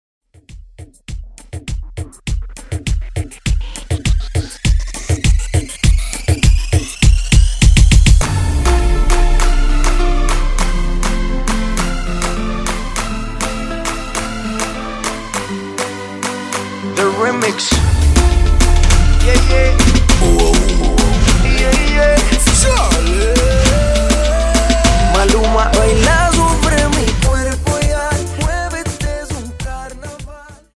Reggaeton